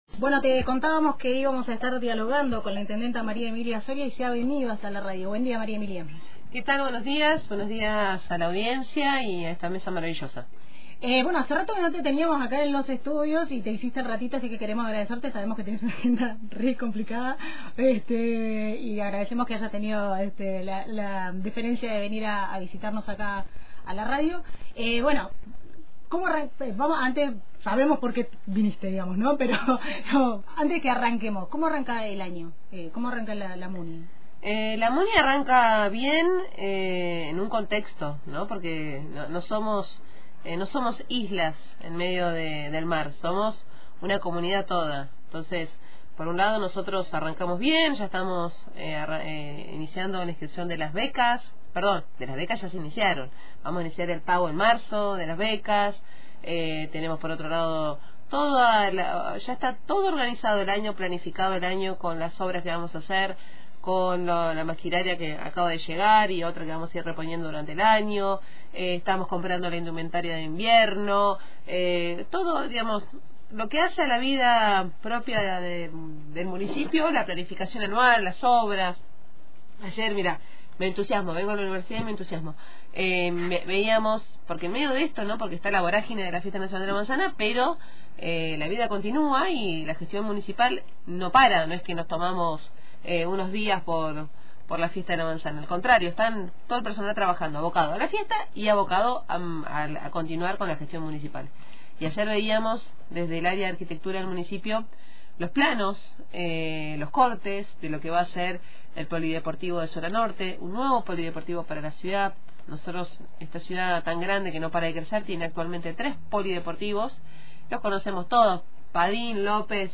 La intendenta de General Roca, María Emilia Soria, visitó los estudios de Antena Libre y repasó el inicio del año municipal en un contexto que definió como complejo.